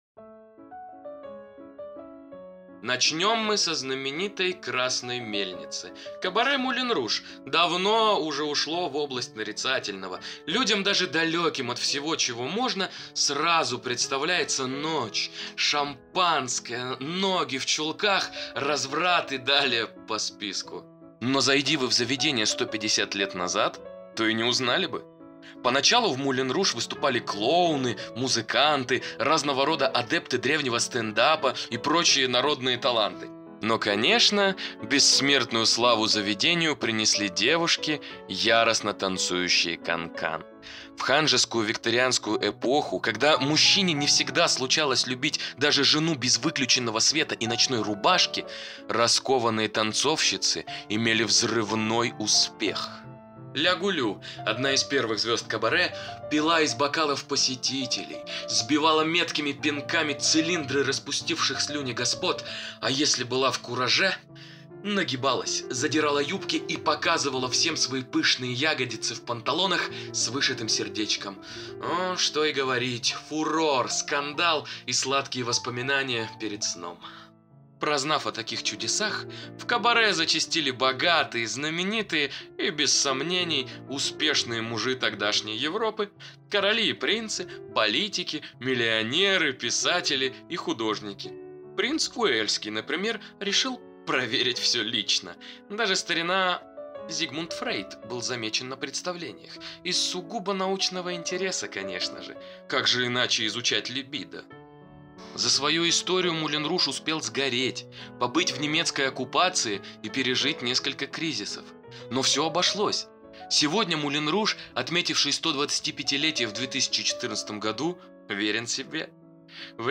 Прогулка по Монмартру с аудиогидом TouringBee: ее не забыть!